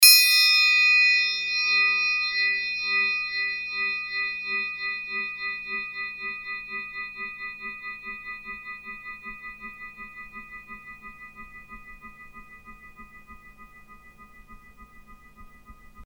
Royalty free music elements: Percussion
mf_SE-189-fx_triangle_1.mp3